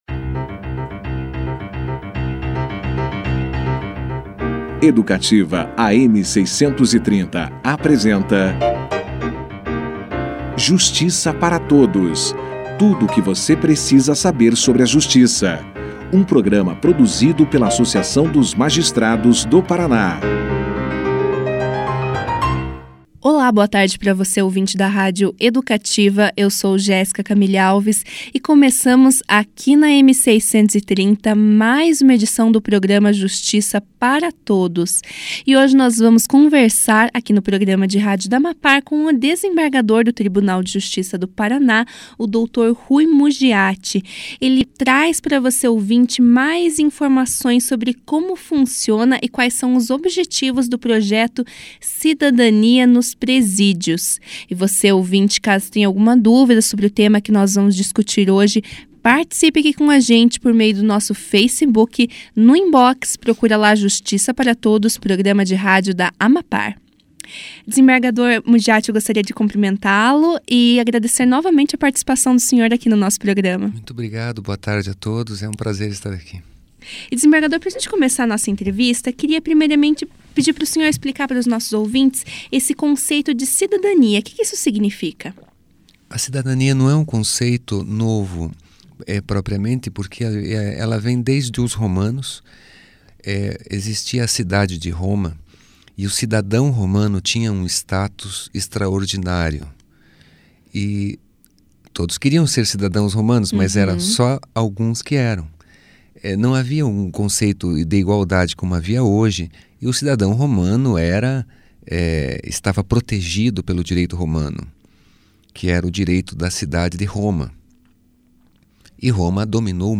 O programa Justiça Para Todos recebeu, nesta sexta-feira (12), o desembargador Ruy Muggiati, que falou a respeito da atuação do Projeto Cidadania nos Presídios – iniciativa do Conselho Nacional de Justiça (CNJ), que visa à melhoria do sistema de execução penal do país. No início da entrevista, Muggiati, contextualizando o assunto, explicou o conceito de cidadania na Antiguidade e na democracia moderna.